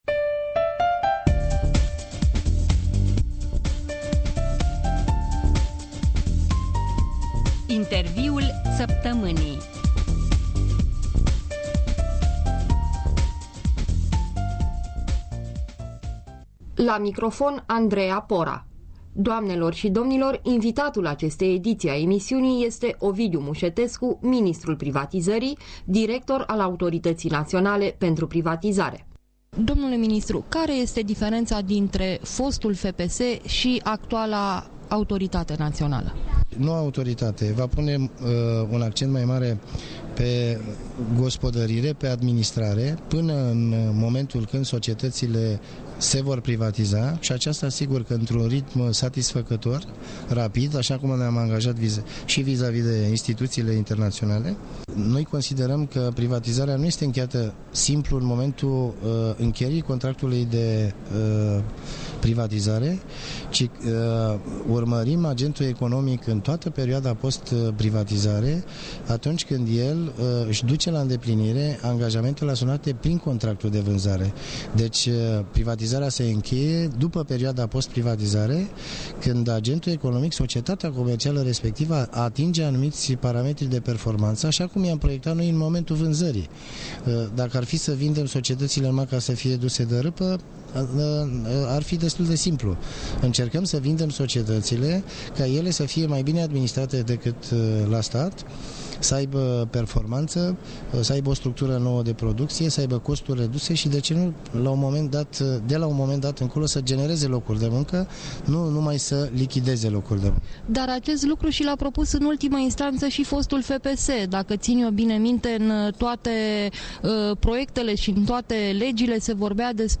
Interviul săptămînii